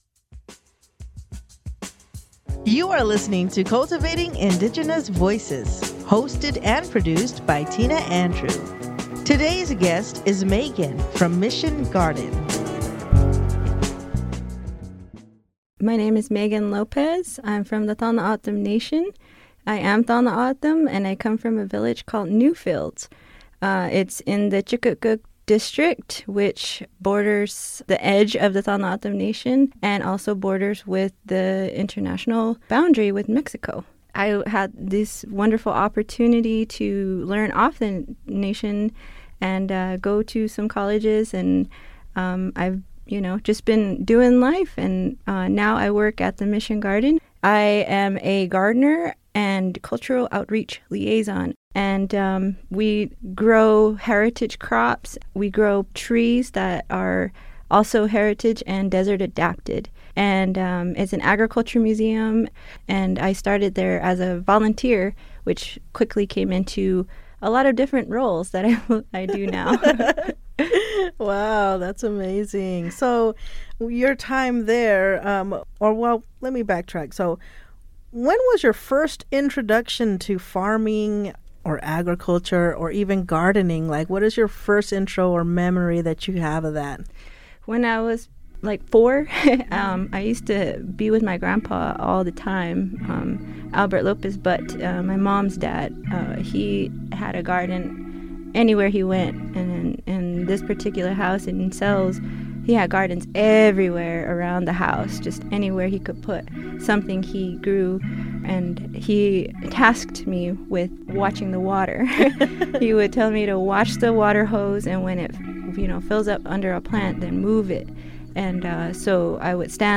Here is a short form interview